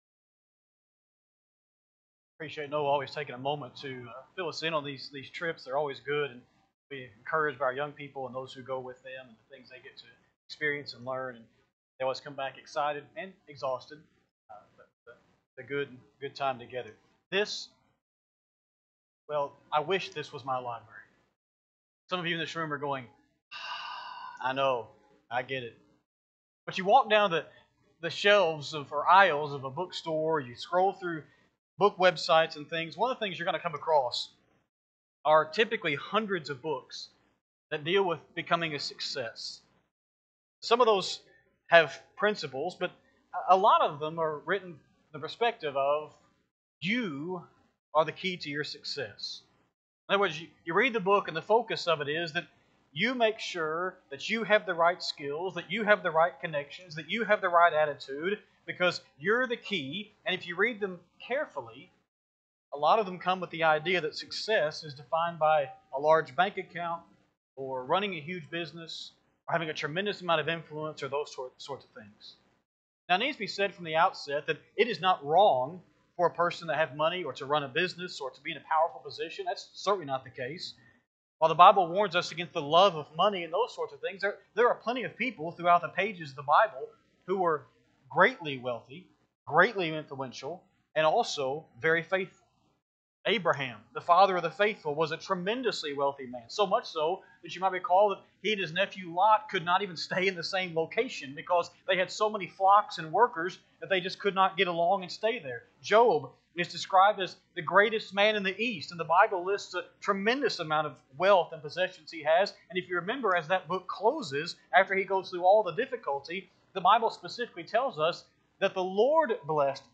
Sunday-PM-Sermon-1-18-26.mp3